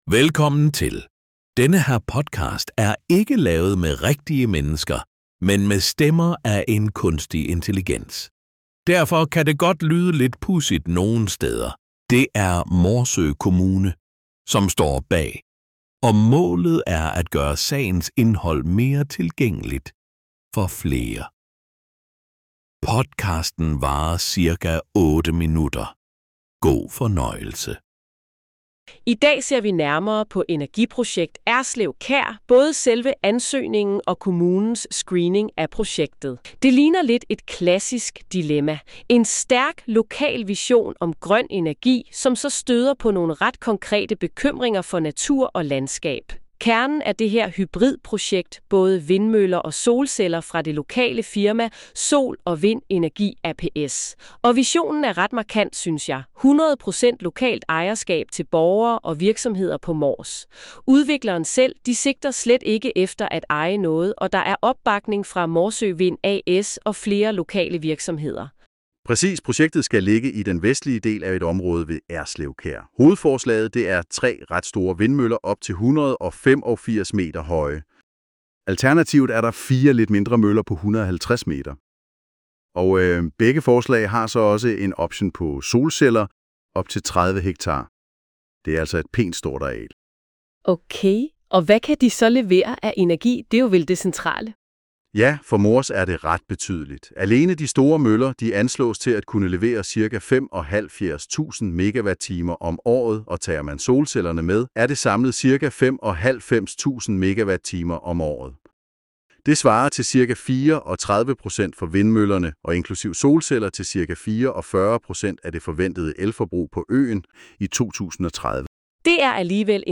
Podcasten er ikke lavet med rigtige mennesker, men med stemmer af en kunstig intelligens. Derfor kan det godt lyde lidt pudsigt nogen steder.